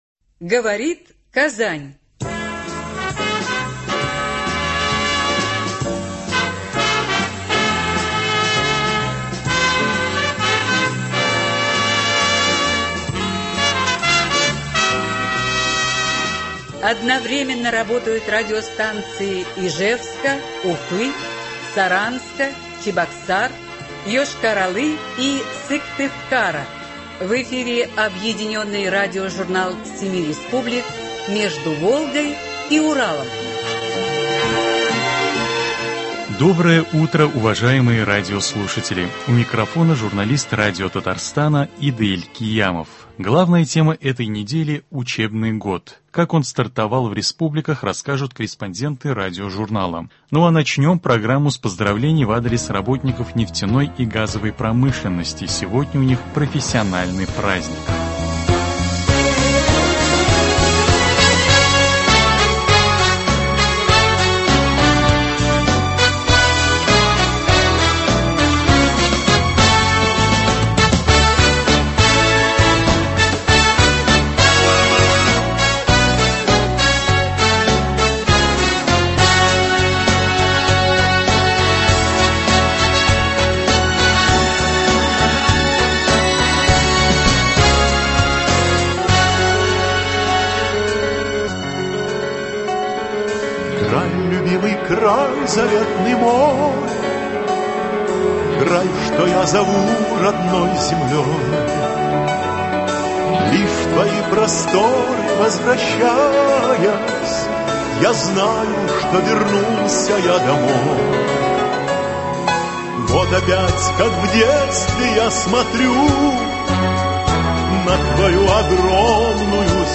Главная тема этой недели — новый учебный год. Как он стартовал в республиках – расскажут корреспонденты радиожурнала.